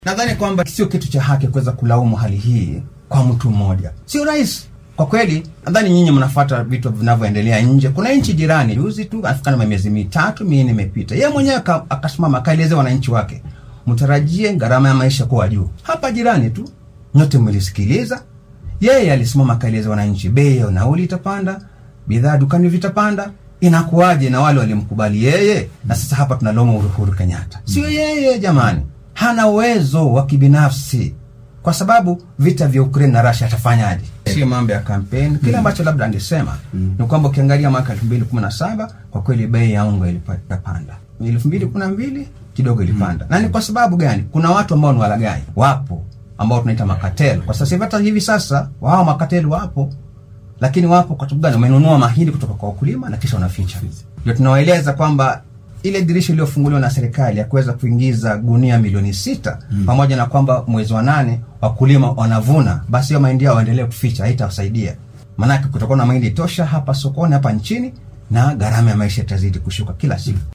Afhayeenka dowladda dhexe Cyrus Oguna oo wareysi siiyay idaacadda Radio Citizen ayaa sheegay in sare u kaca maciishadda ee ay hadda Kenyaanka wajahayaan aynan ahayn arrin lagu eedeyn karo madaxweyne Uhuru Kenyatta.